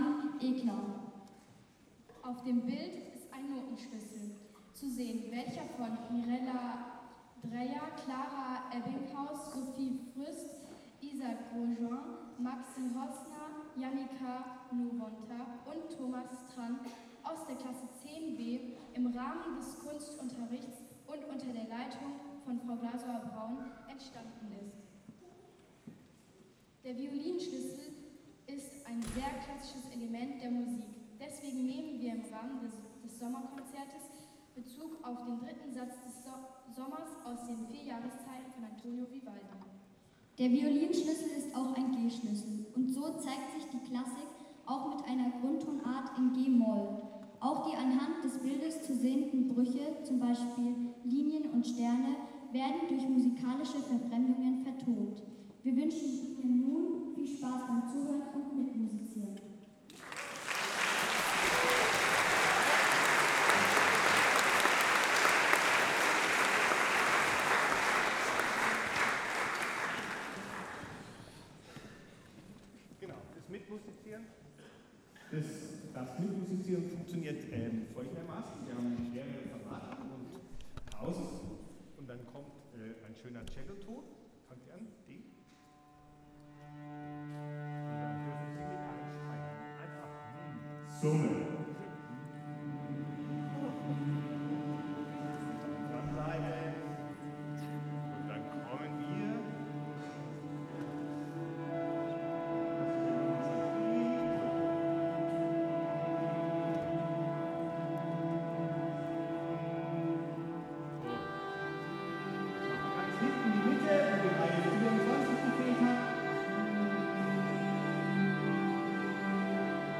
Auch das Publikum wurde durch Summen teil der Musik.  Als Höhepunkt der Komposition ertönten einzelne, explosive Klänge, die den gelb/violetten Stern im Fokus hatten.
Die Uraufführung war zum Sommerkonzert des CSG 2023.
Version mit Moderation und Einführung
Orchestrale Aufführung des Stücks